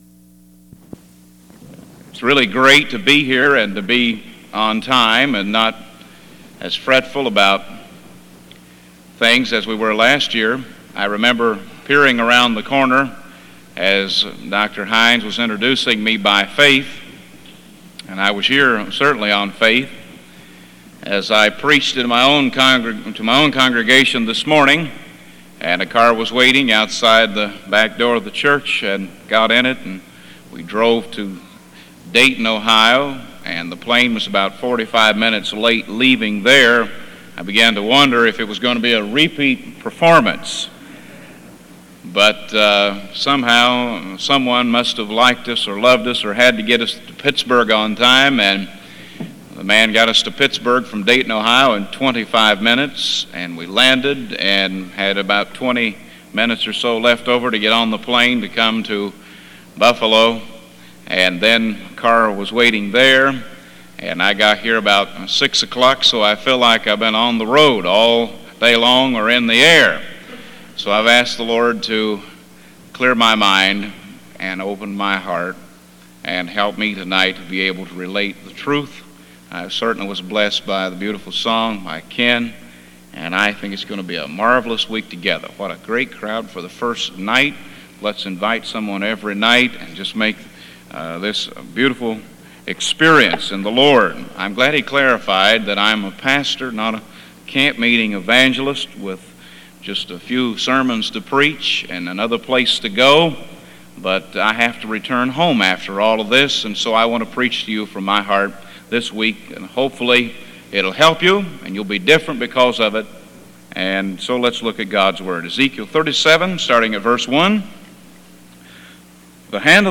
Houghton Bible Conference 1982
"Dry Bone" revival message